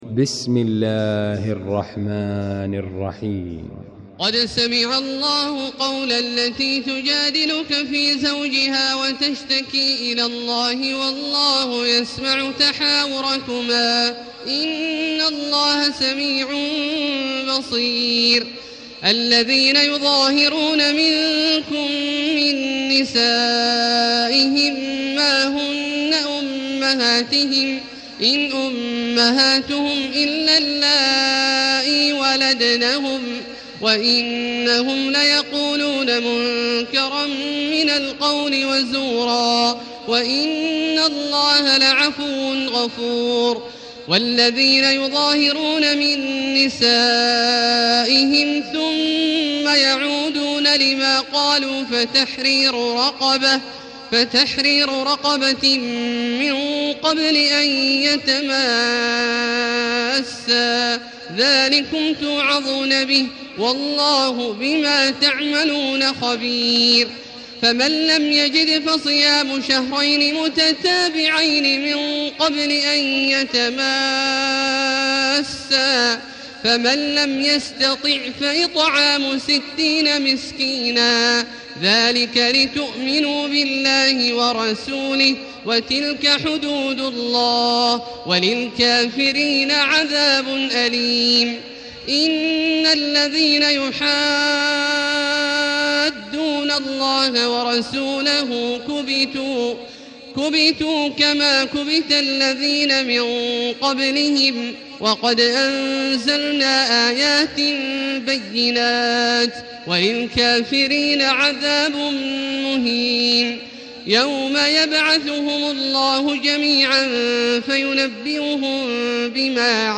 المكان: المسجد الحرام الشيخ: فضيلة الشيخ عبدالله الجهني فضيلة الشيخ عبدالله الجهني المجادلة